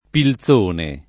[ pil Z1 ne ]